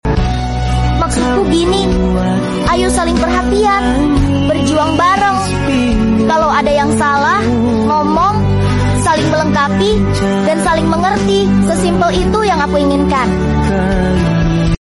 animasi berbicara buatan Ai